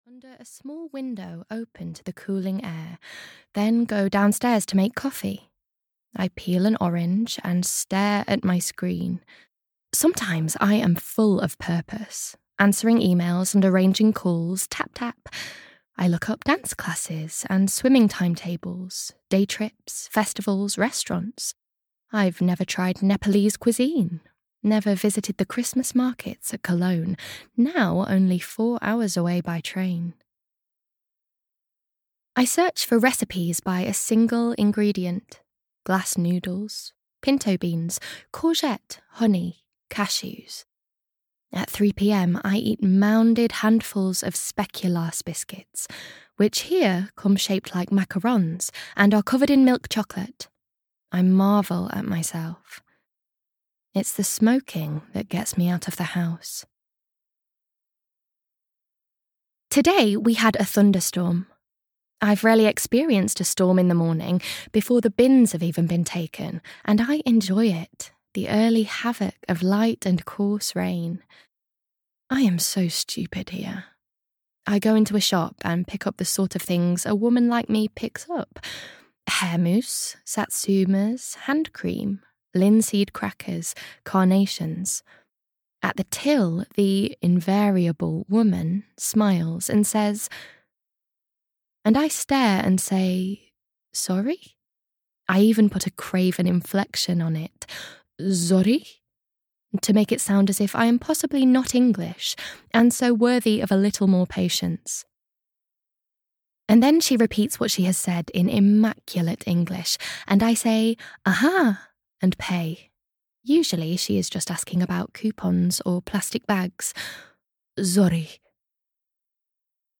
Temper (EN) audiokniha
Ukázka z knihy